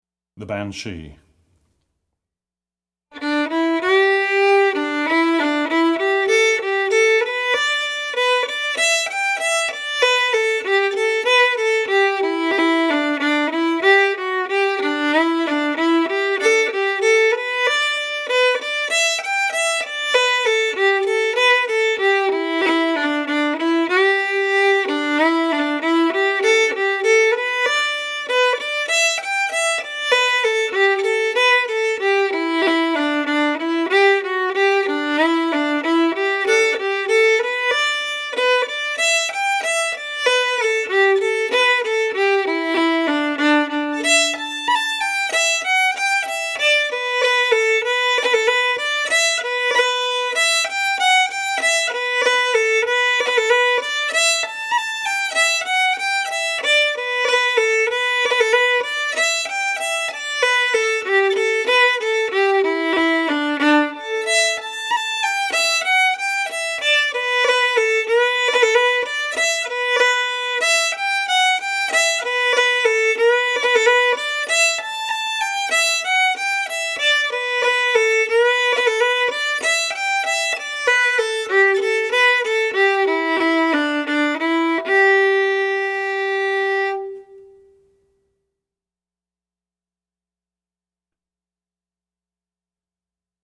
FIDDLE SOLO Fiddle Solo, Celtic/Irish, Reel
DIGITAL SHEET MUSIC - FIDDLE SOLO